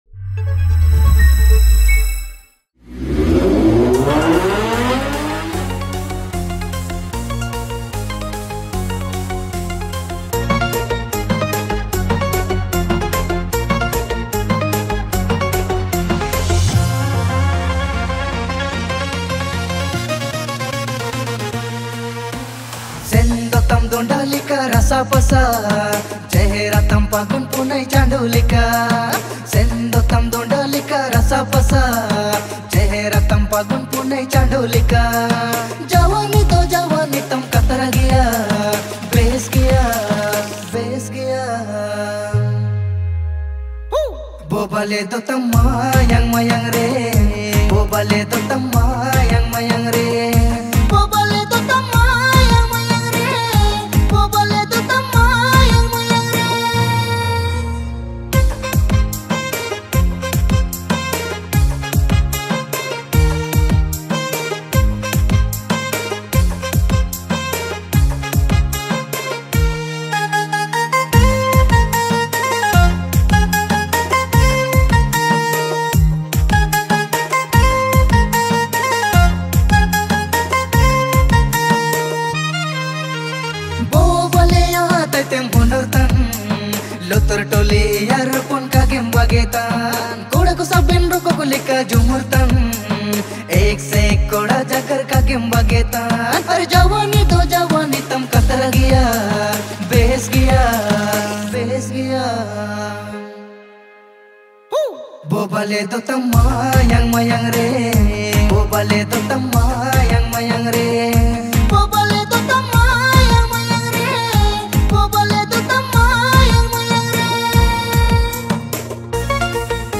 Releted Files Of Nagpuri